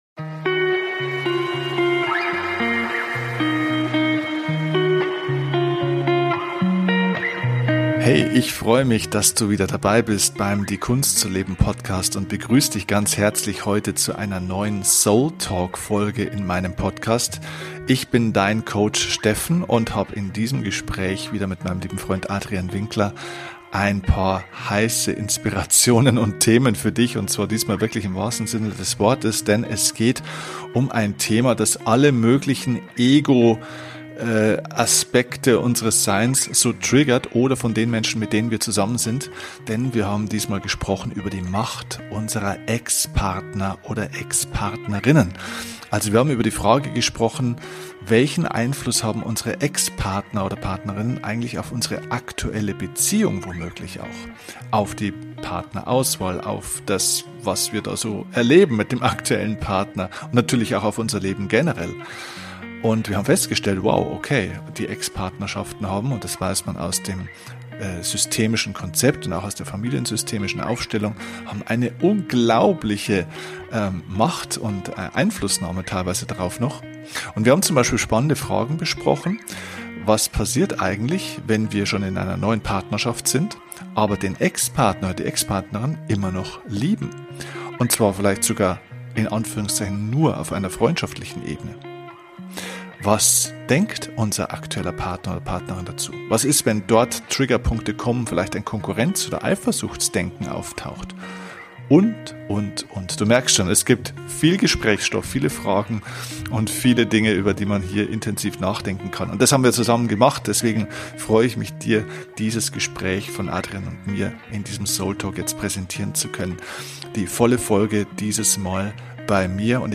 Wie immer heißt es: kein Skript; einfach unser ehrliches Gespräch.